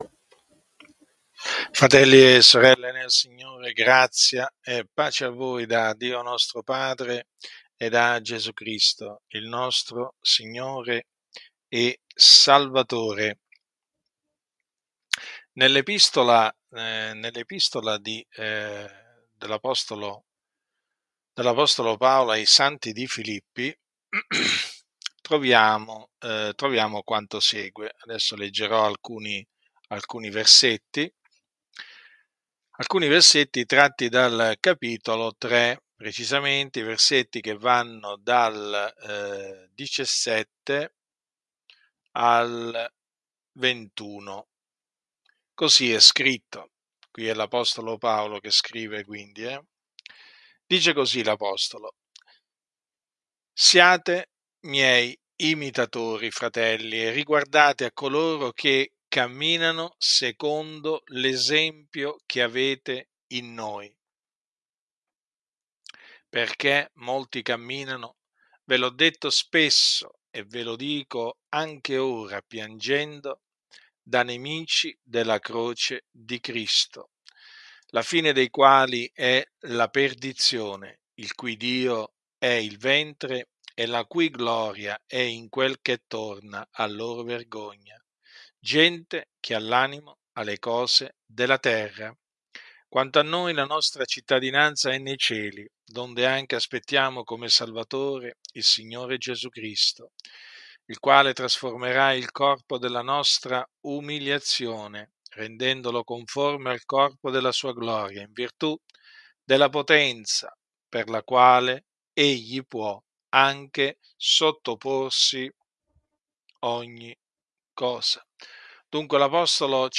Per accedere all’archivio completo delle registrazioni, visita: Archivio Predicazioni